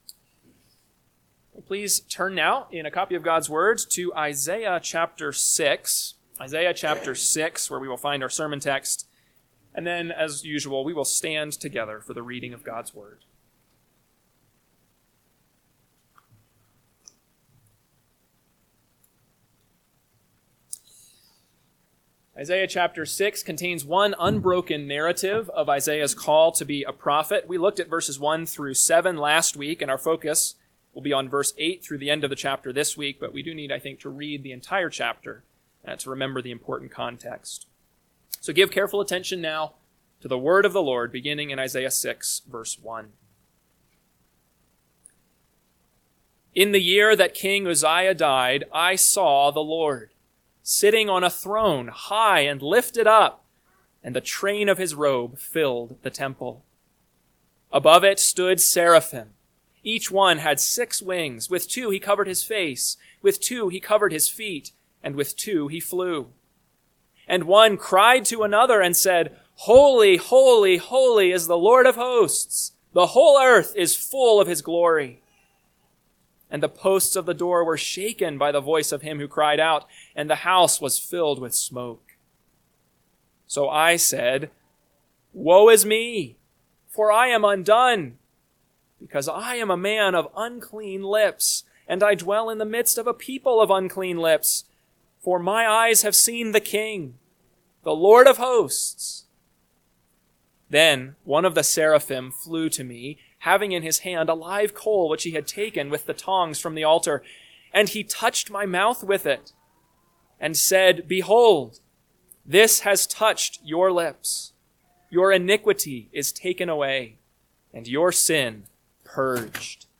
AM Sermon – 12/14/2025 – Isaiah 6:8-13 – Northwoods Sermons